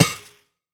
Monster_Spawner_break2_JE1_BE1.wav